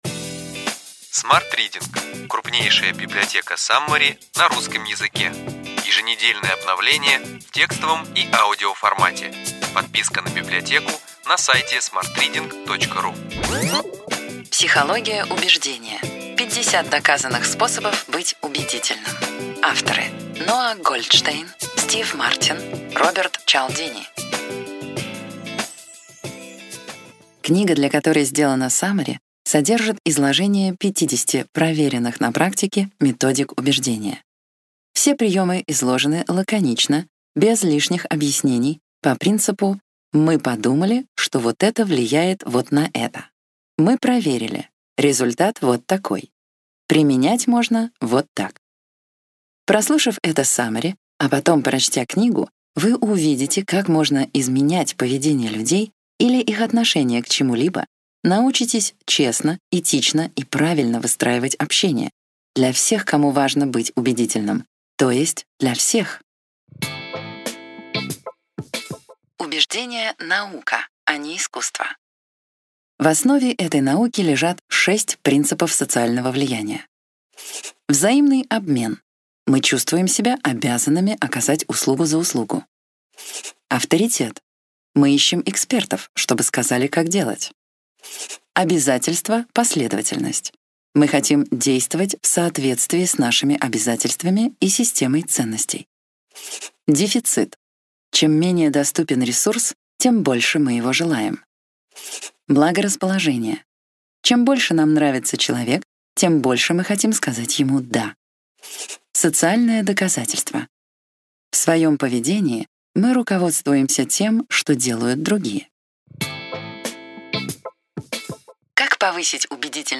Аудиокнига Ключевые идеи книги: Психология убеждения. 50 доказанных способов быть убедительным.